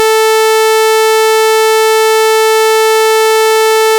Вот две пилы, слышно и в колонках, и в наушниках. Перевёрнутый файл выкупается как более высокий.